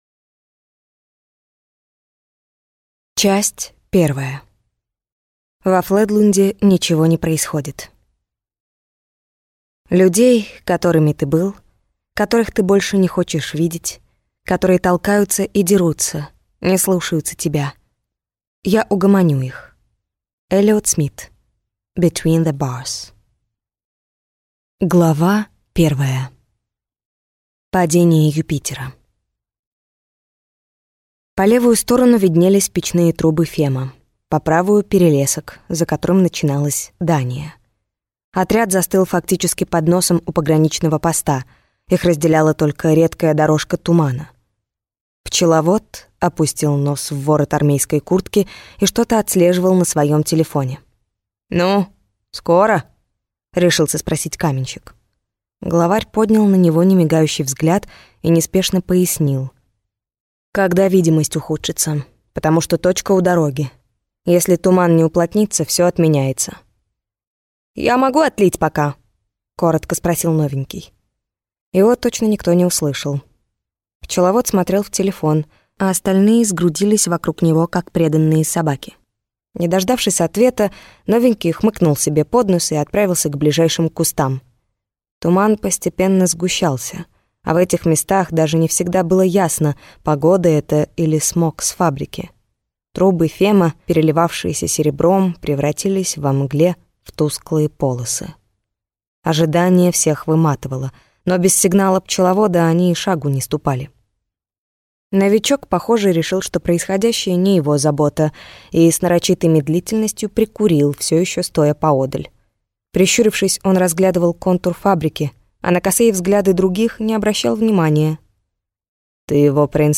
Аудиокнига Фледлунд | Библиотека аудиокниг